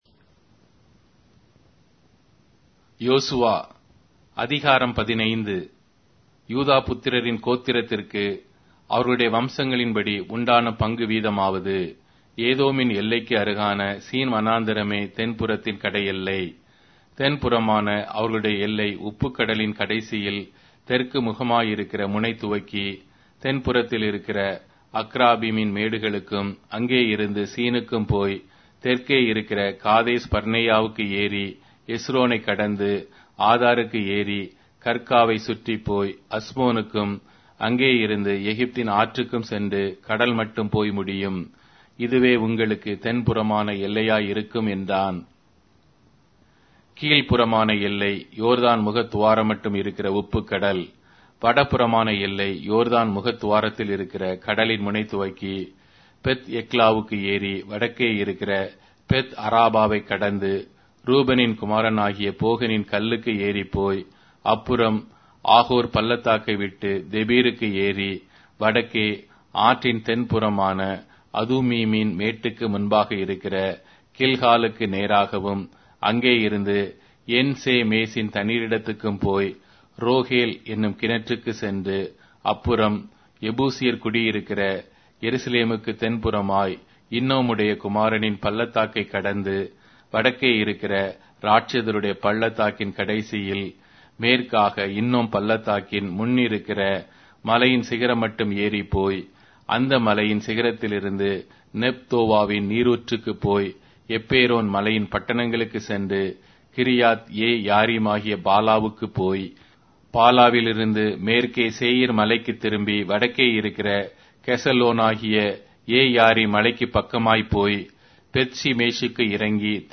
Tamil Audio Bible - Joshua 4 in Net bible version